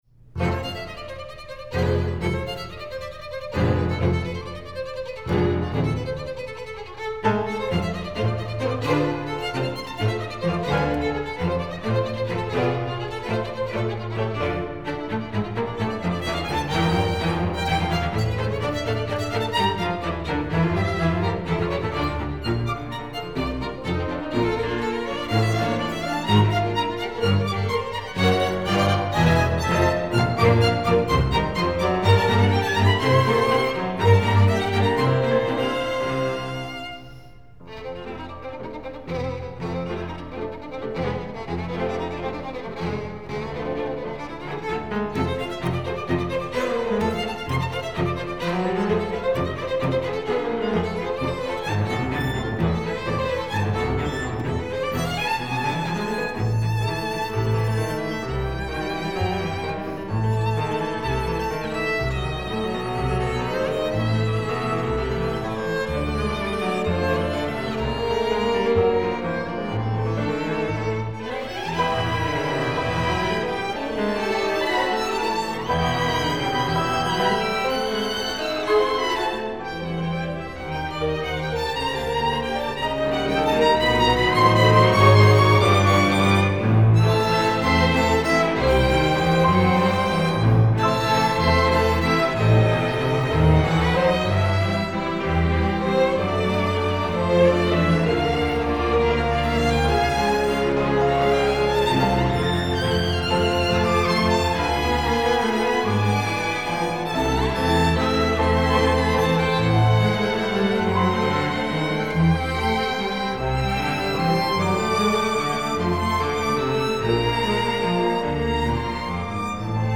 9Bughici_Vivace.mp3